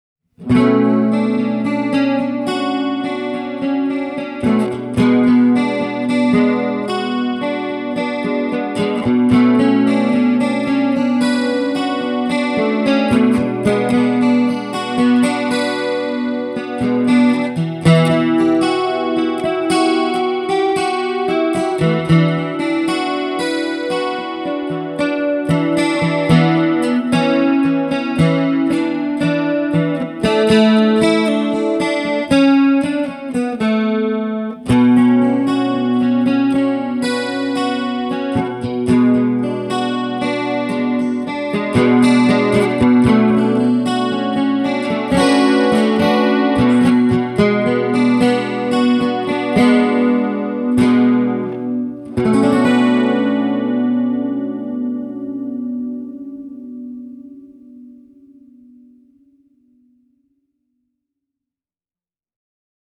Äänitin seuraavat pätkät mikittämällä komboa:
plektra ja Wide-chorus ja Reverb-kaiku
plectrum-chorus.mp3